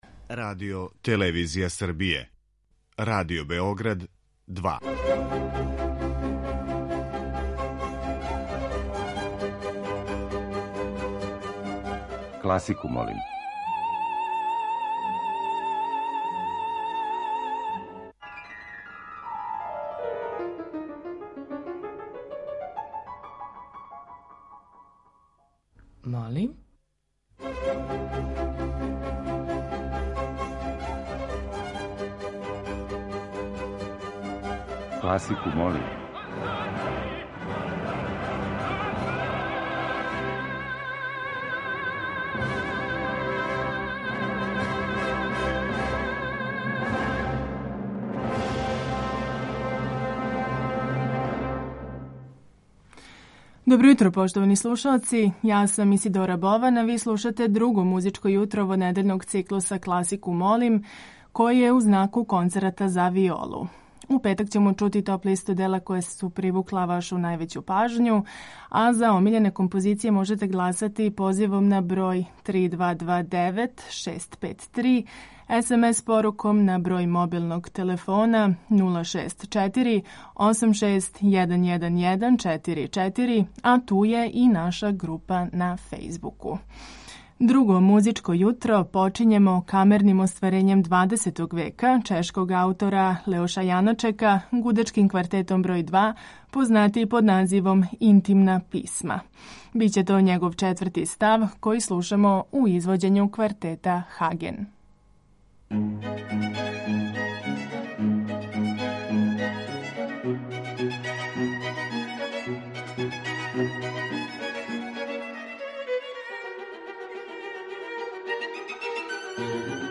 Тема циклуса носи наслов 'Концерти за виолу'.